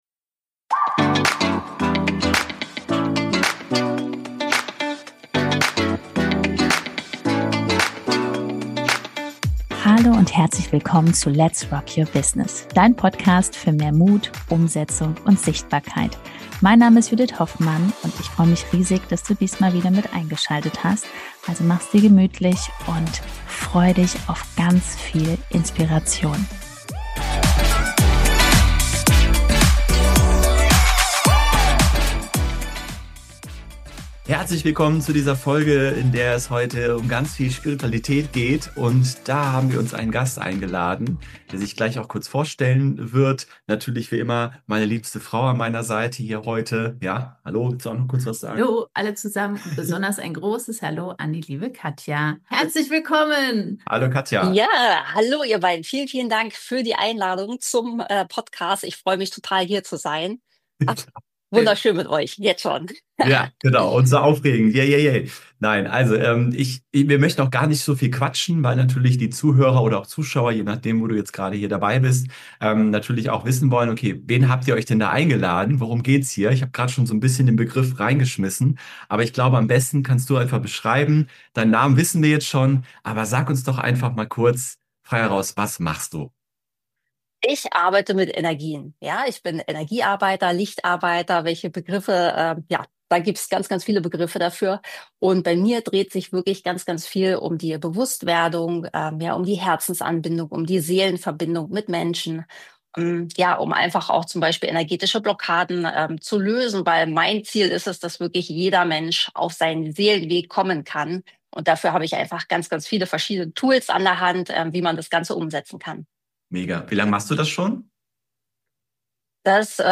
372 - Interview